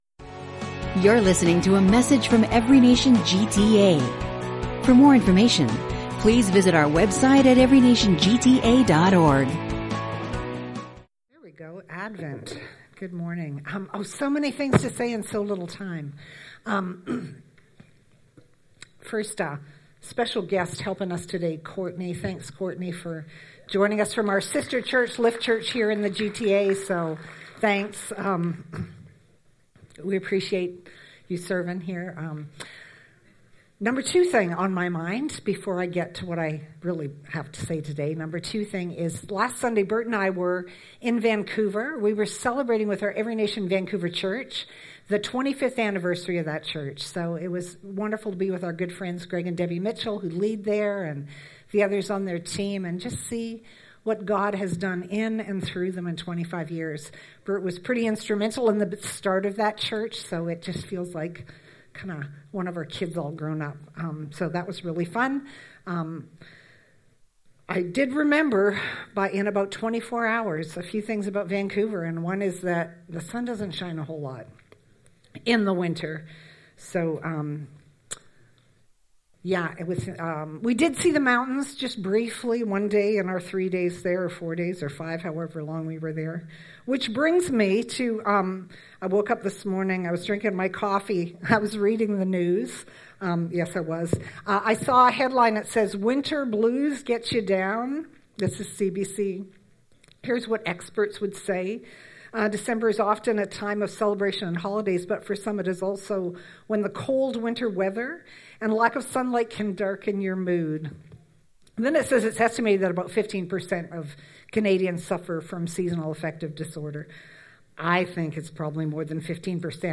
Advent MMXXV: Christmas Carols Service - Every Nation GTA | Church Toronto